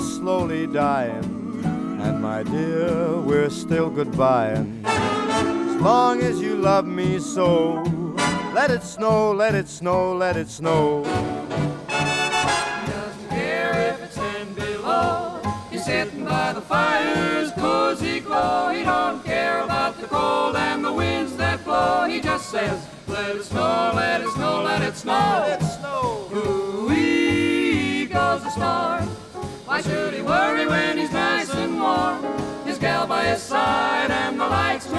# Vocal Jazz